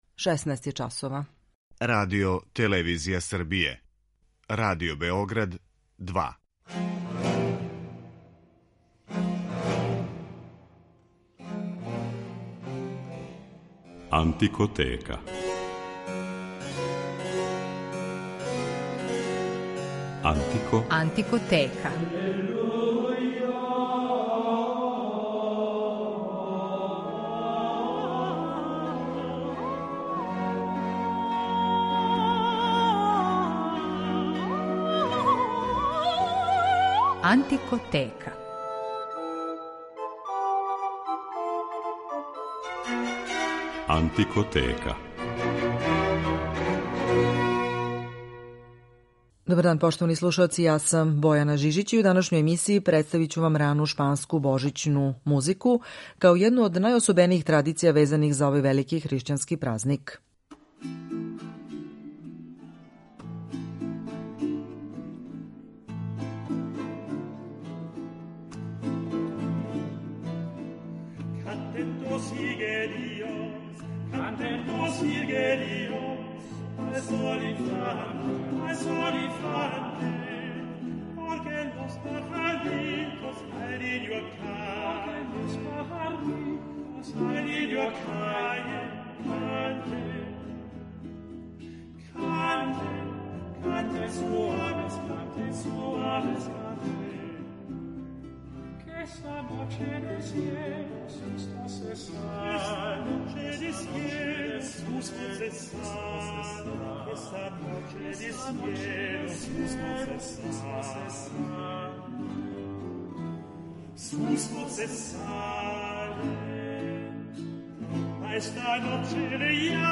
У данашњој емисији представићемо вам рану шпанску божићну музику као једну од најособенијих традиција везаних за овај велики хришћански празник.